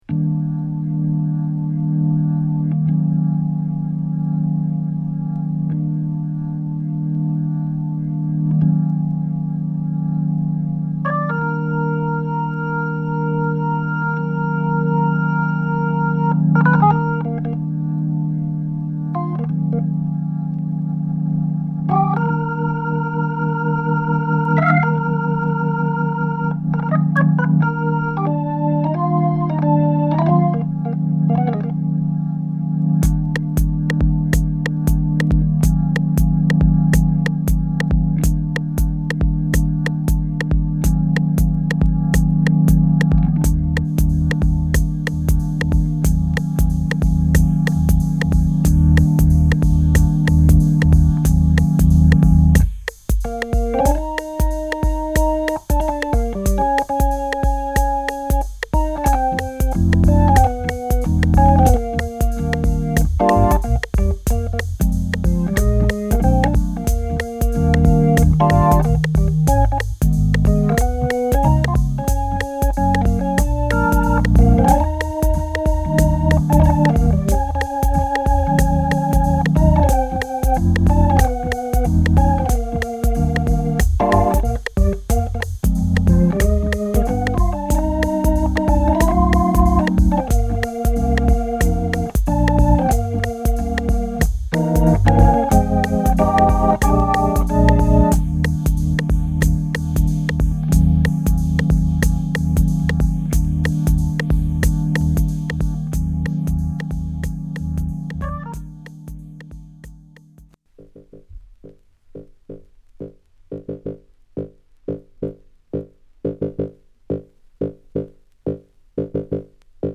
両サイド共にホッコリ暖かな質感が堪らないグッドチューンを収録！